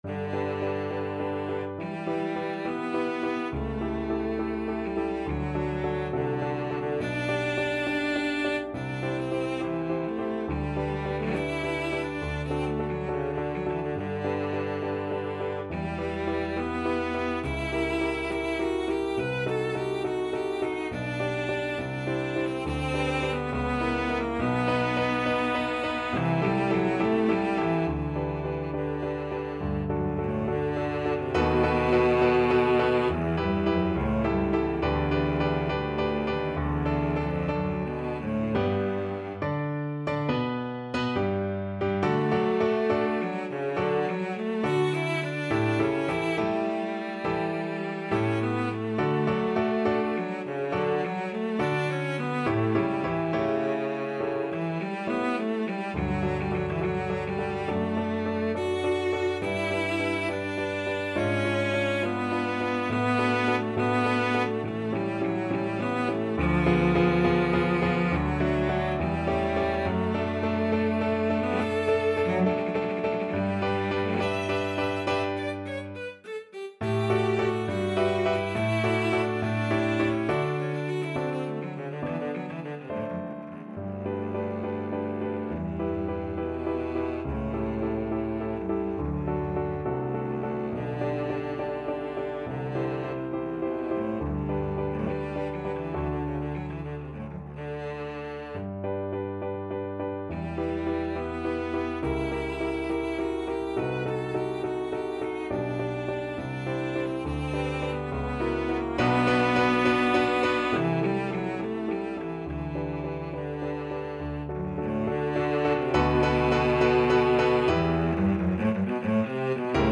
Cello version
4/4 (View more 4/4 Music)
Classical (View more Classical Cello Music)